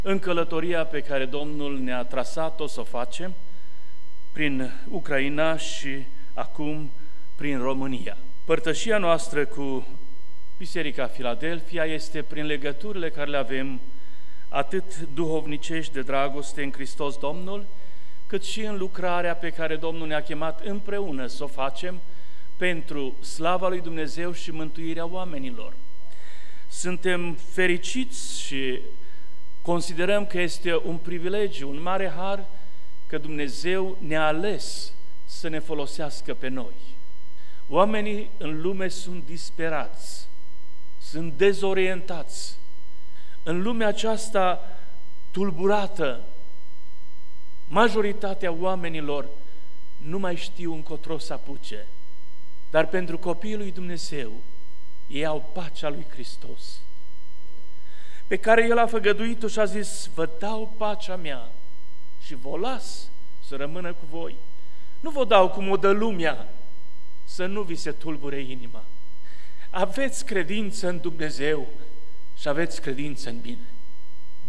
Iată un scurt fragment din mesajul său: